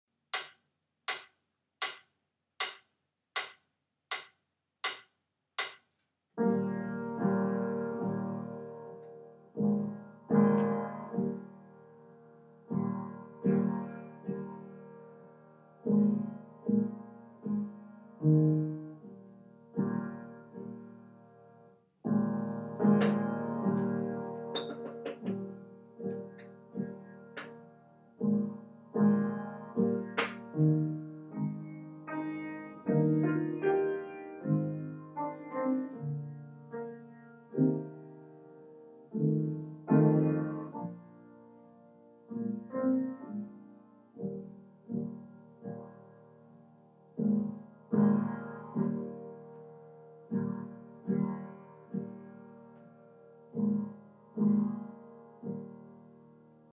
Prelude Piano Only